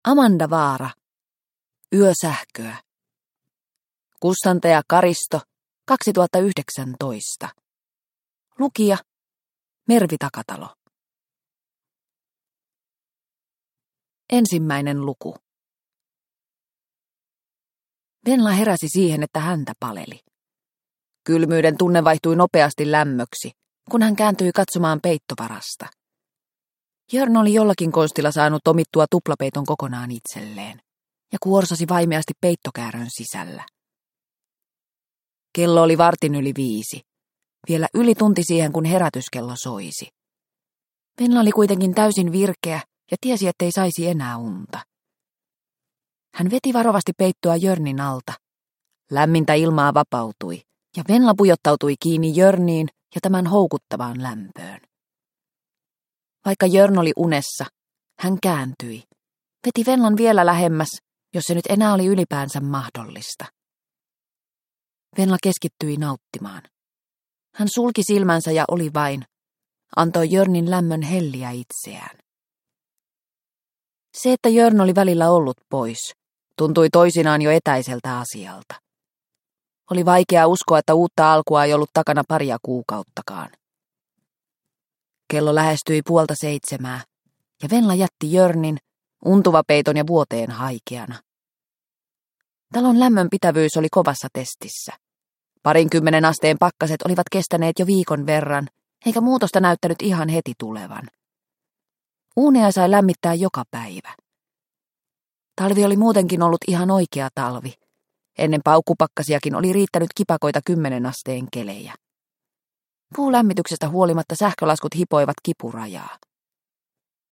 Yösähköä – Ljudbok